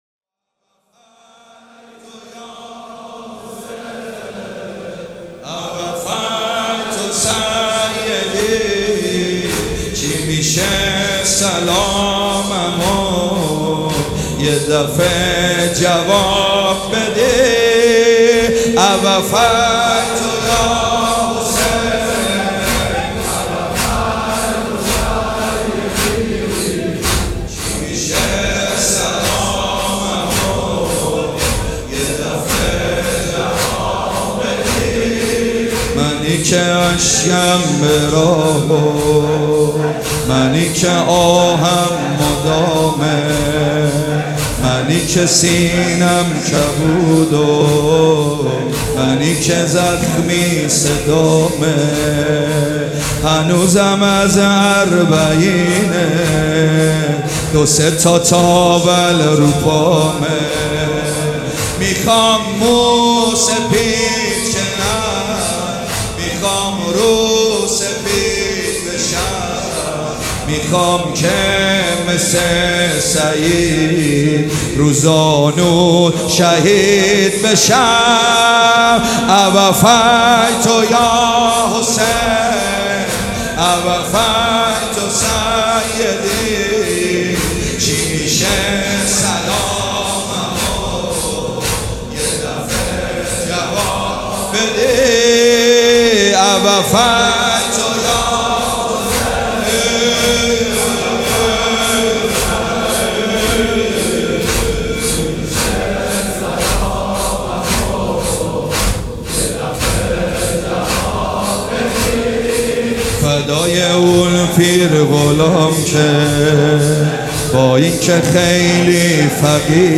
مداح
مراسم عزاداری شب دوم